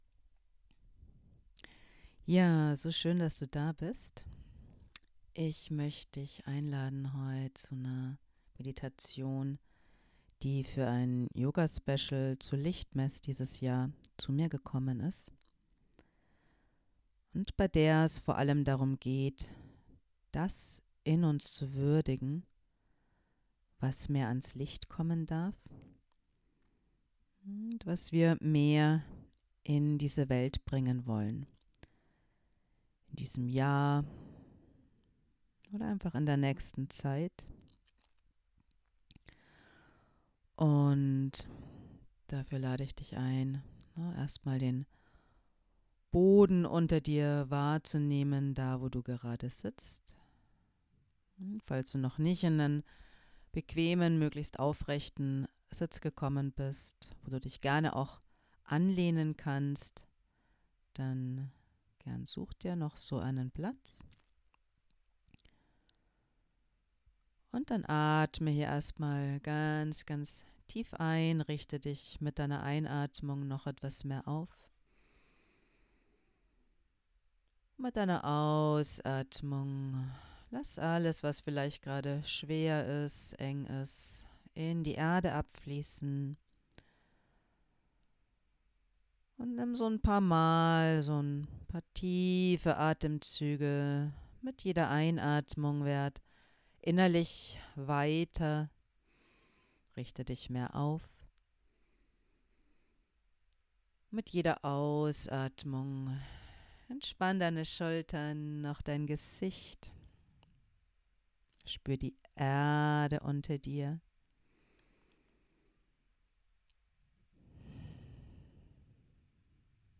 Heil-Meditation mit Reinigung (11 Minuten)